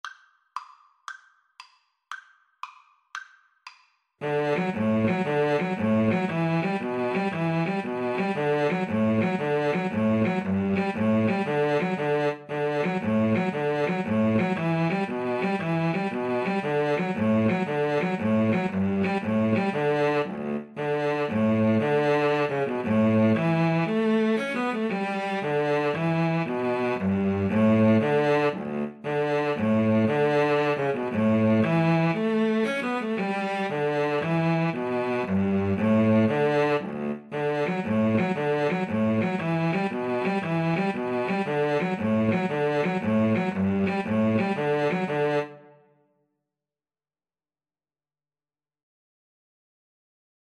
Free Sheet music for Violin-Cello Duet
D major (Sounding Pitch) (View more D major Music for Violin-Cello Duet )
6/8 (View more 6/8 Music)
Allegro .=c.116 (View more music marked Allegro)
Traditional (View more Traditional Violin-Cello Duet Music)